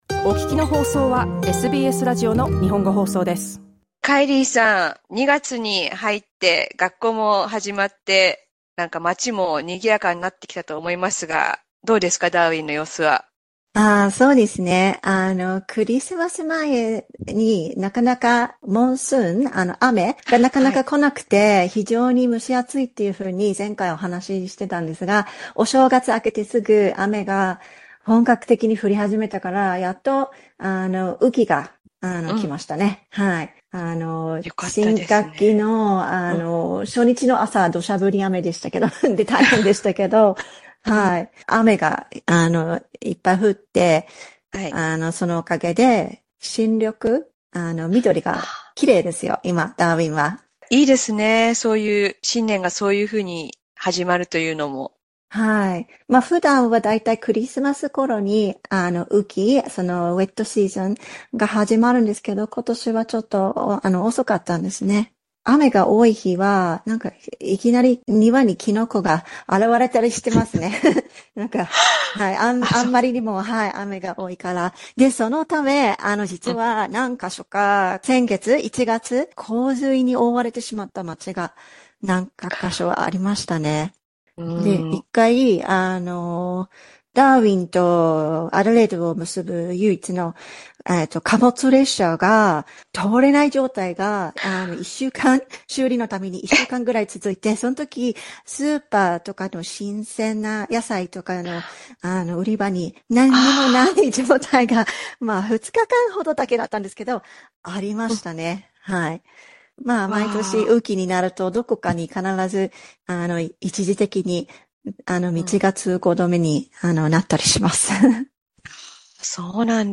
火曜日放送の国内各地の話題や情報をお伝えするコーナー、オーストラリアワイドから。